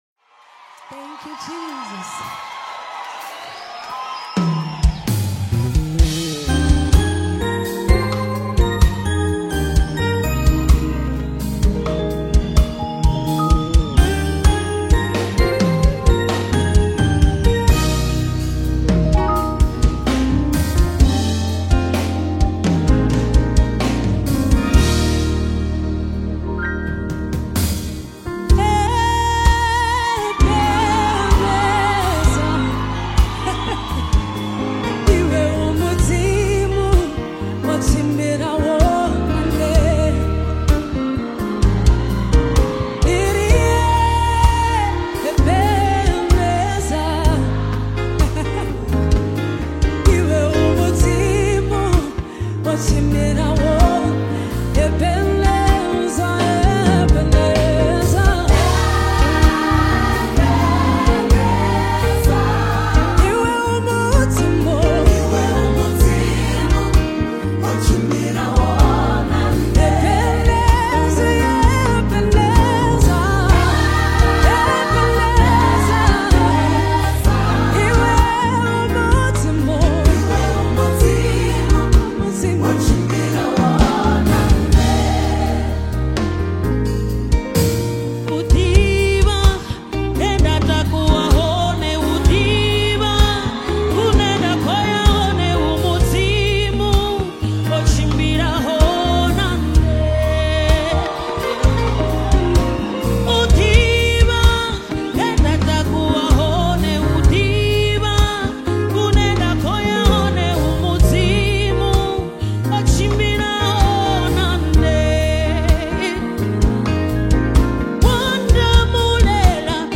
South African Gospel
Genre: Gospel/Christian